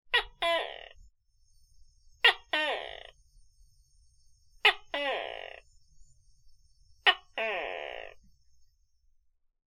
Чистый звук геккона в разговоре